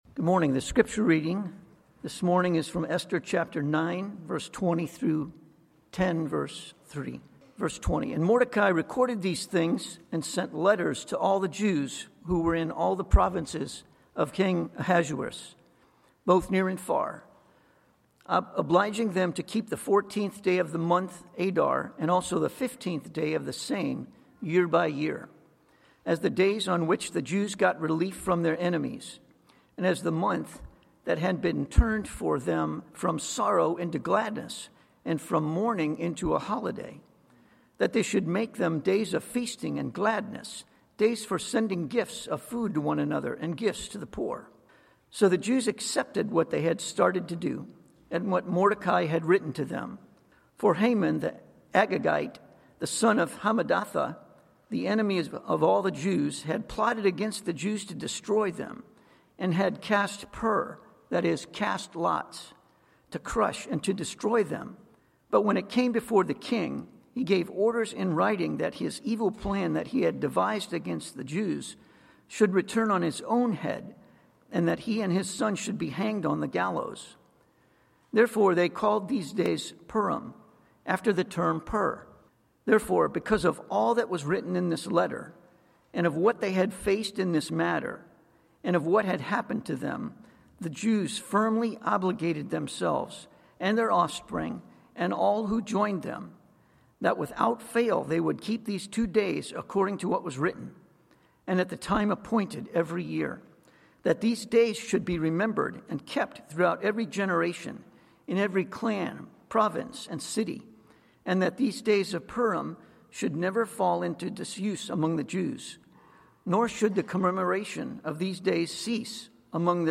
Passage: Esther 9:20-10:3 Sermon